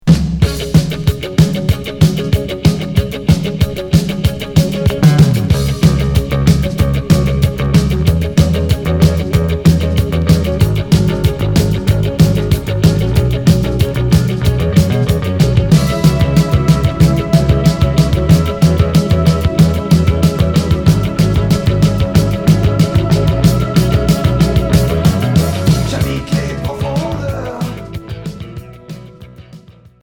Rock new wave Unique 45t retour à l'accueil